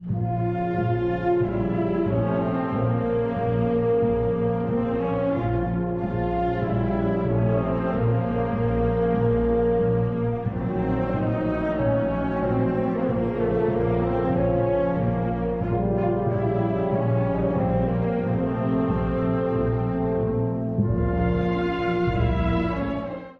古い音源なので聴きづらいかもしれません！（以下同様）
葬送行進曲の雰囲気のように、程よい速さで」という意味です。
重苦しいファゴットとビオラによる挽歌から始まります。